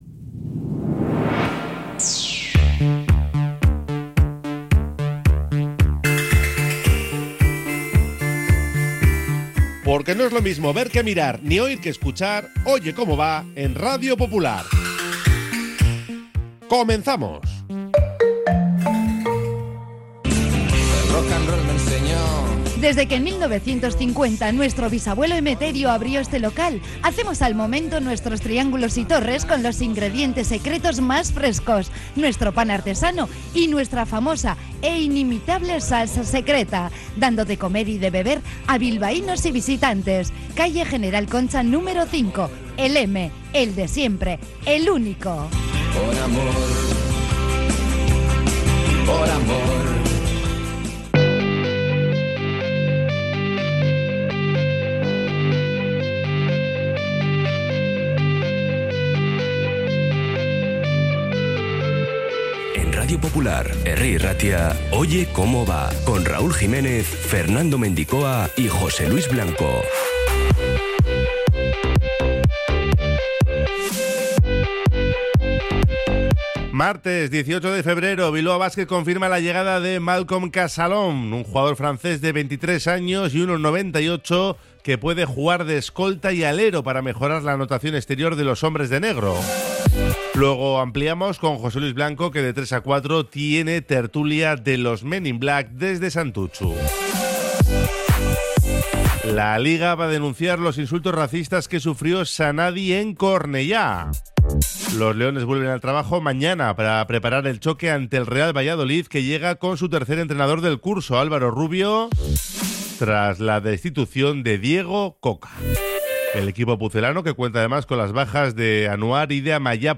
De 13:30 a 14:00 resumen informativo de la jornada con entrevistas y las voces de los protagonistas.